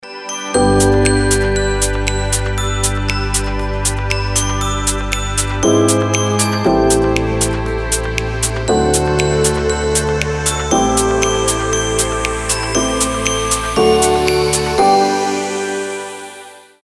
• Качество: 320, Stereo
мелодичные
без слов
колокольчики
звонкие
рождественские
Добрая рождественская мелодия.